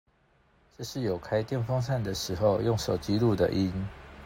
▼ iPhone 12 Pro Max 開電風扇製造風聲環境下錄音檔